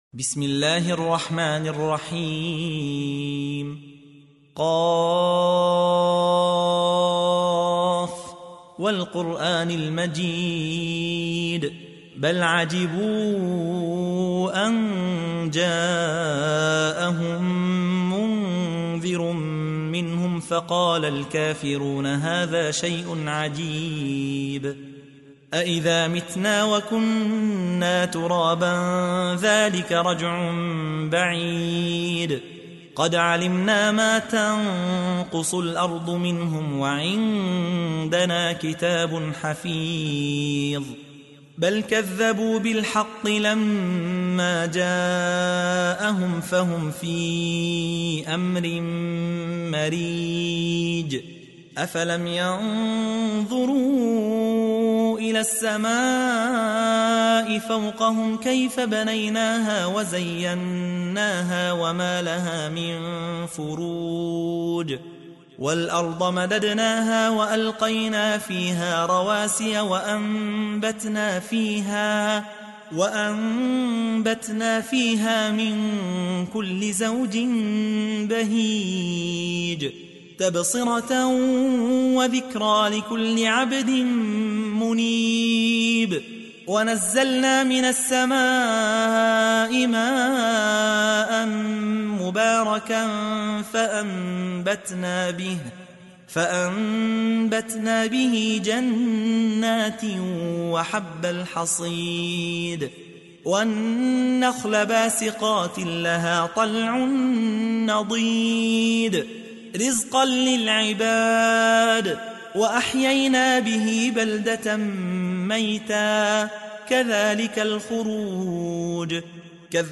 تحميل : 50. سورة ق / القارئ يحيى حوا / القرآن الكريم / موقع يا حسين